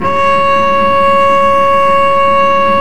Index of /90_sSampleCDs/Roland - String Master Series/STR_Vc Marc&Harm/STR_Vc Harmonics